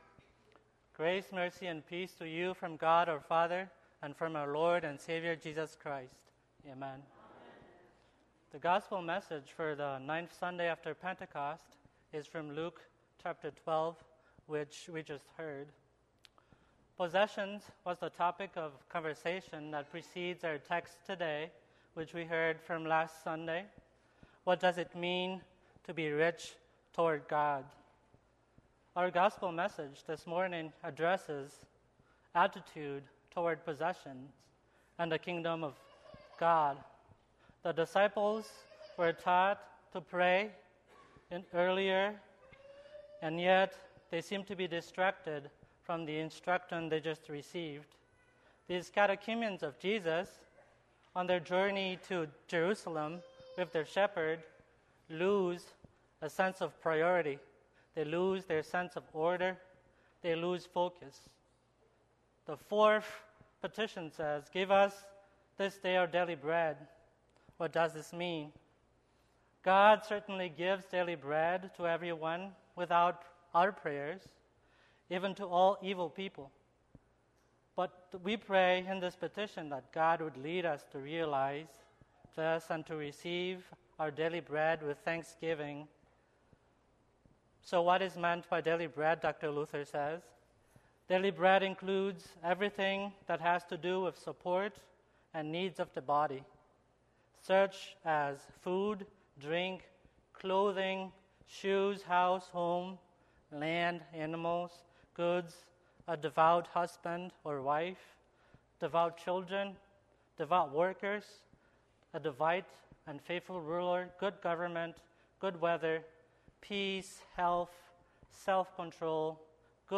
Ninth Sunday after Pentecost
Sermon_Aug7_2022.mp3